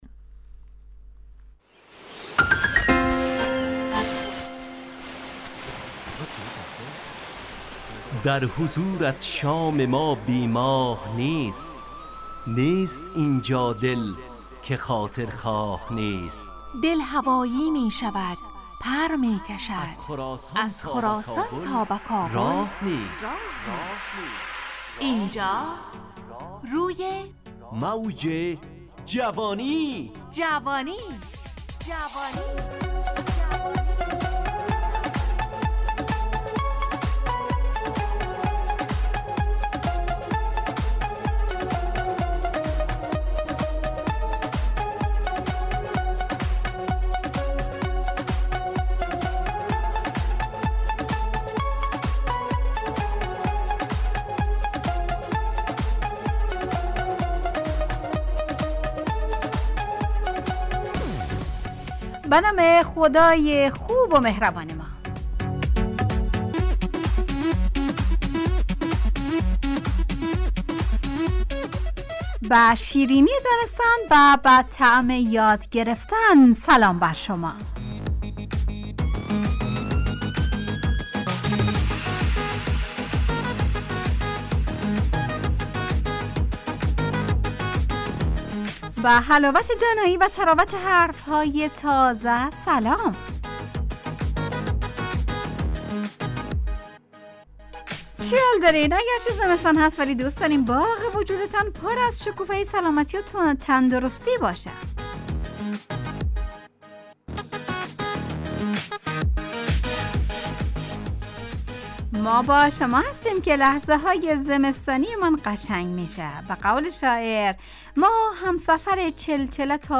همراه با ترانه و موسیقی مدت برنامه 55 دقیقه . بحث محوری این هفته (سواد) تهیه کننده